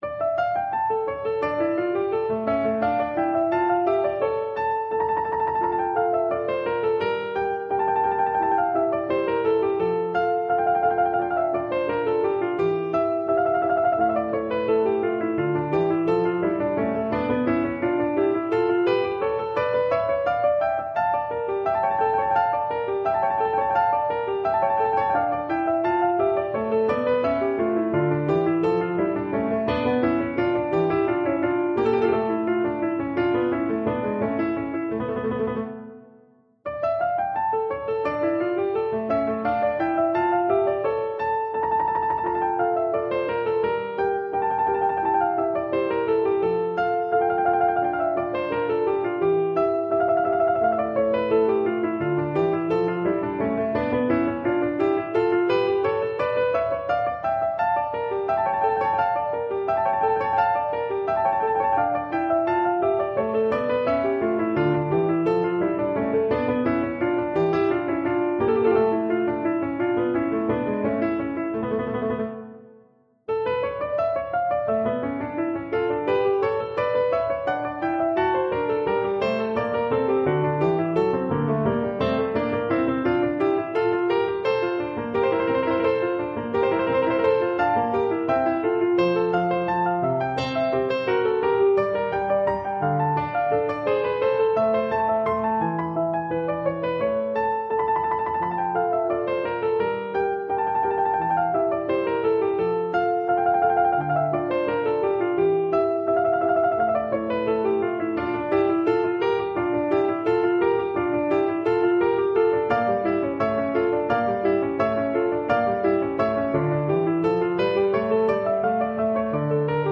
Originally composed as an exercise, it features large leaps.
» 442Hz
• Duet (Violin / Viola)